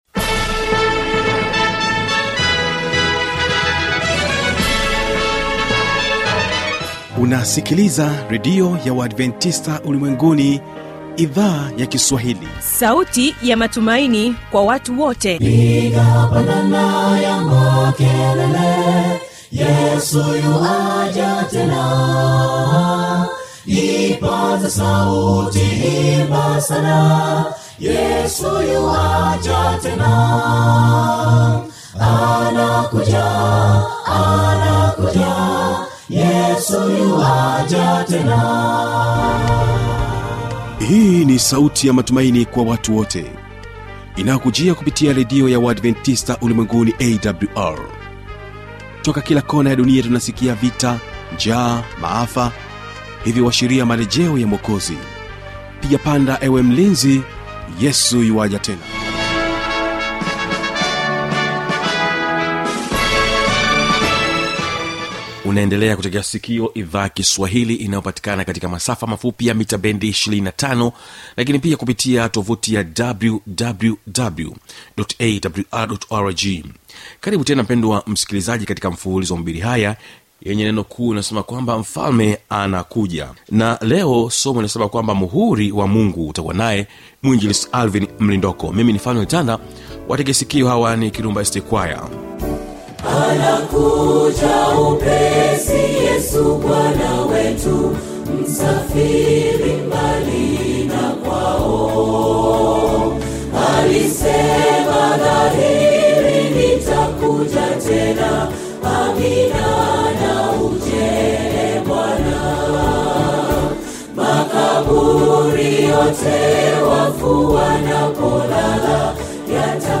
Hubiri